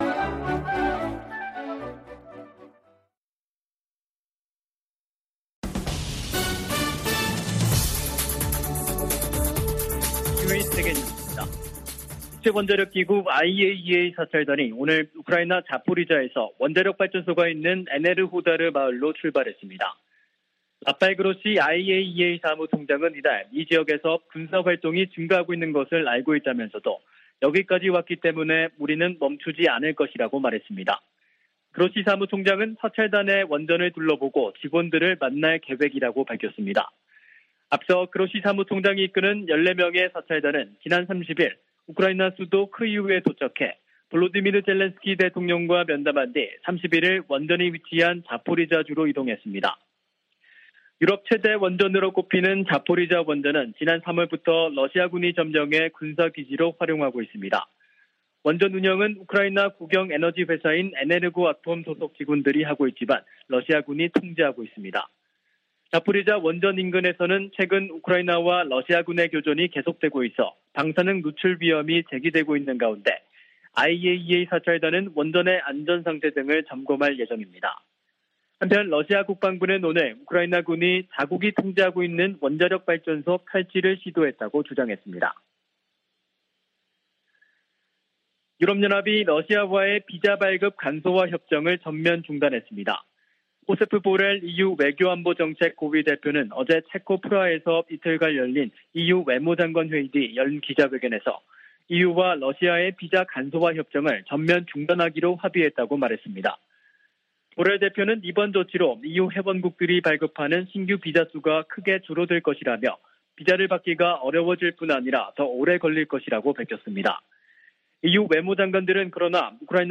VOA 한국어 간판 뉴스 프로그램 '뉴스 투데이', 2022년 9월 1일 3부 방송입니다. 한국에서 실시된 미한 연합군사연습, 을지프리덤실드(UFS)가 1일 끝났습니다. 미 국방부 부장관이 북한을 미국이 직면한 중대한 위협 가운데 하나로 지목하고, 도전에 맞서기 위한 기술 혁신이 필수적이라고 강조했습니다. 1일 하와이에서 열리는 미한일 안보수장 회동에서는 북한의 추가 도발 가능성에 대한 공조 방안이 집중 논의될 것이라고 미 전직 관리들은 전망했습니다.